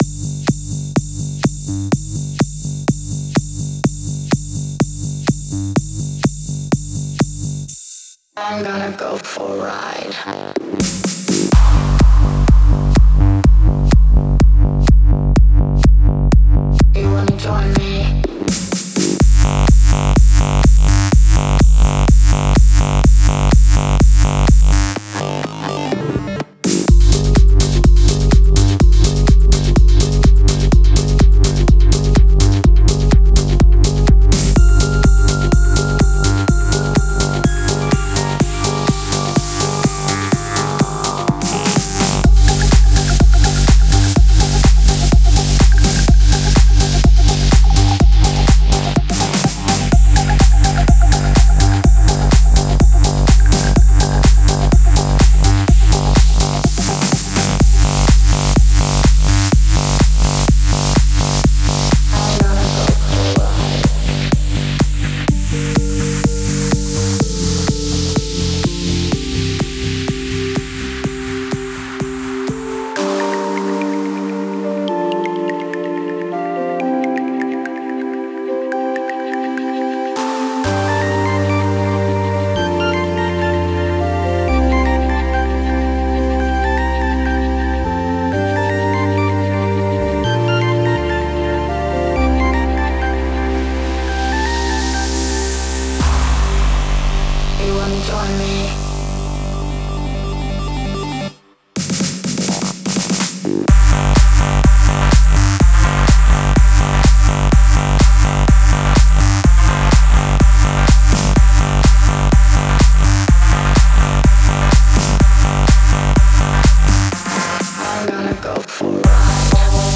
энергичная электронная композиция
выполненная в жанре тек-хаус.